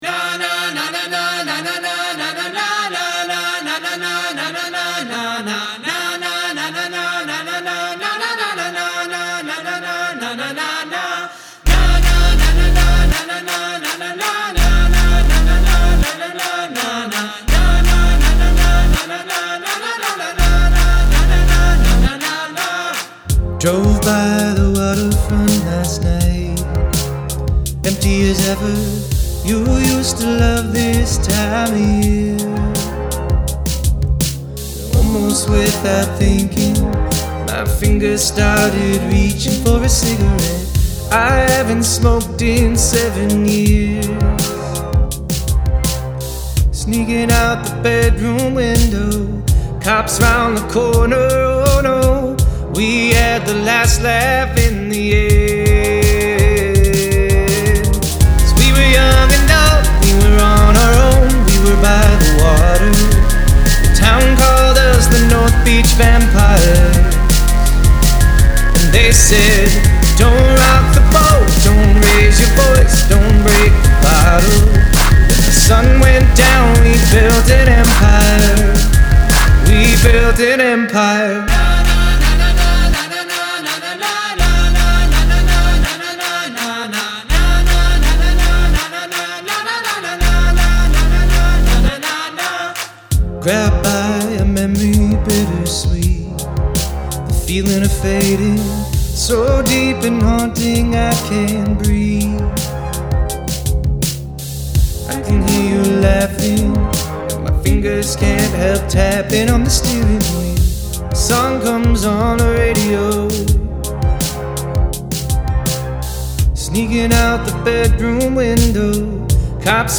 Include an a cappella section
Fantastically melancholy and catchy.